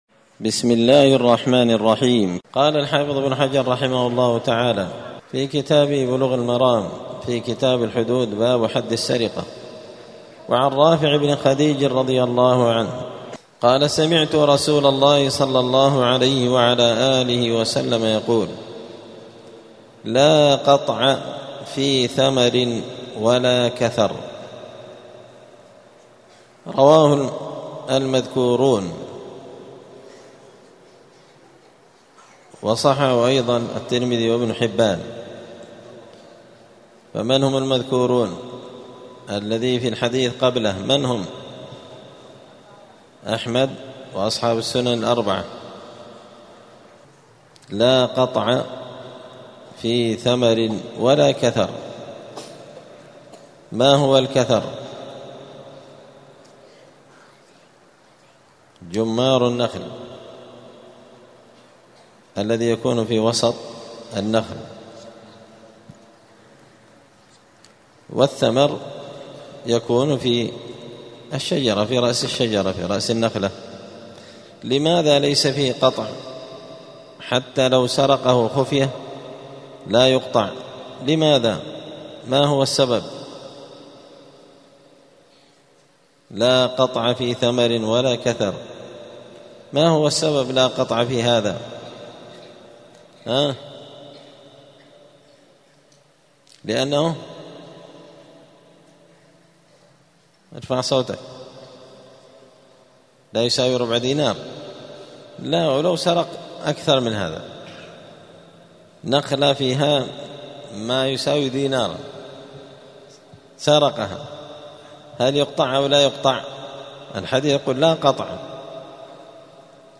*الدرس الثالث والعشرون (23) {باب حد السرقة لا يجوز القطع في سرقة الثمر والكثر}*